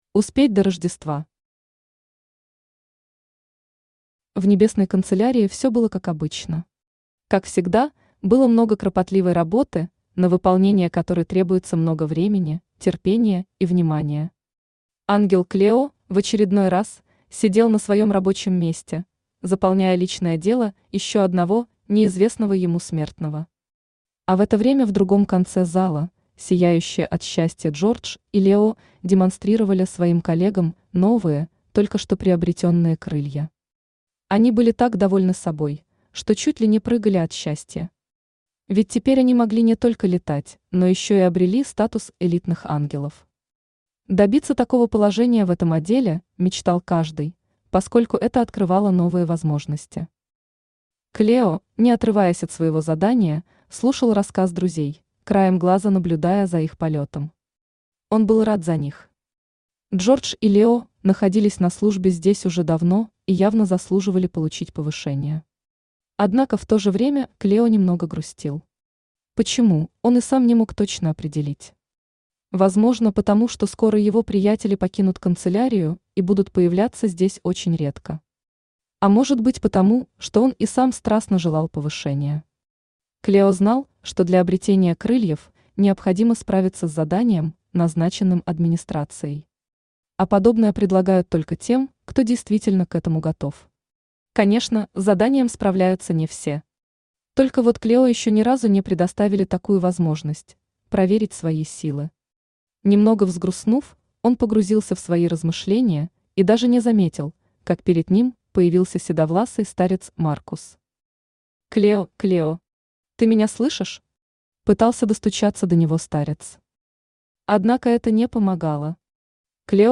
Аудиокнига Успеть до Рождества | Библиотека аудиокниг
Aудиокнига Успеть до Рождества Автор Алена Юльевна Никишкина Читает аудиокнигу Авточтец ЛитРес.